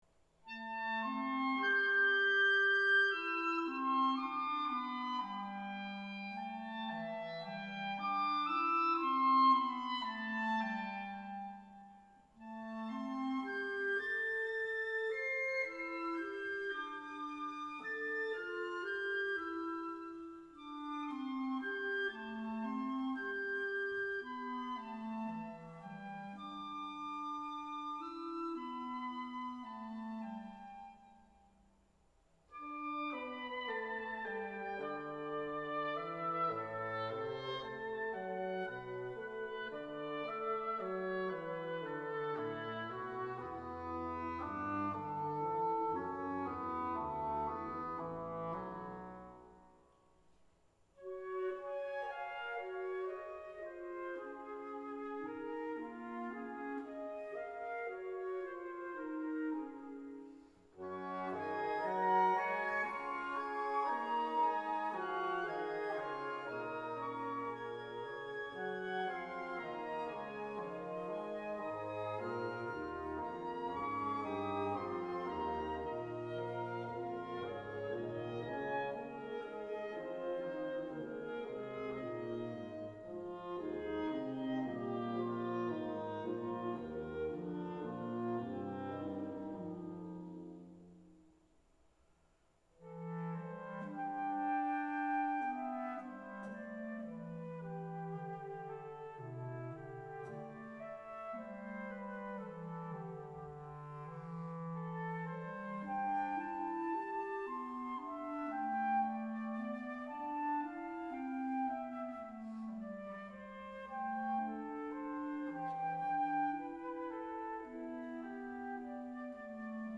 Concerto for Orchestra 管弦樂協奏曲 32 minutes